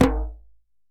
DJEM.HIT18.wav